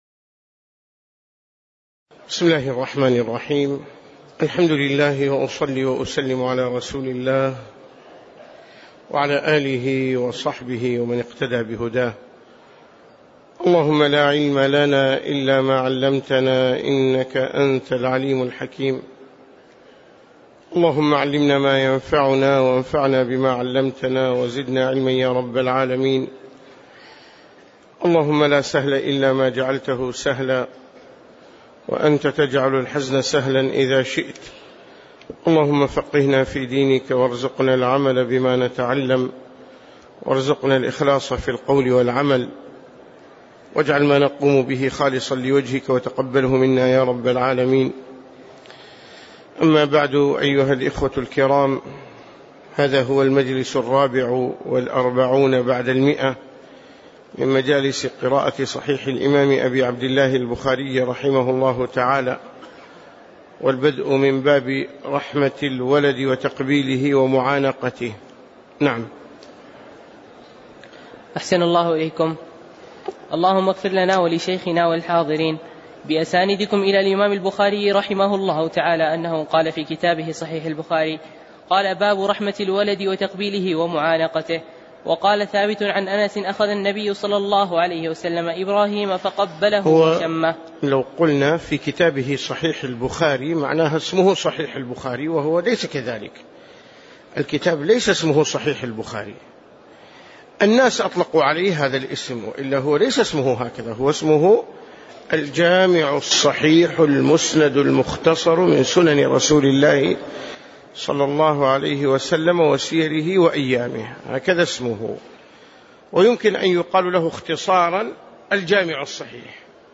تاريخ النشر ١٢ محرم ١٤٣٩ هـ المكان: المسجد النبوي الشيخ